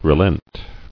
[re·lent]